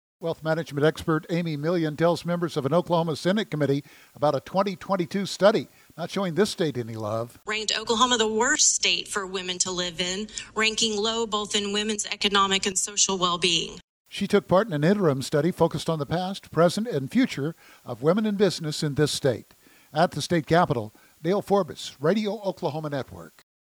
tells members of an Oklahoma Senate committee about a 2022 study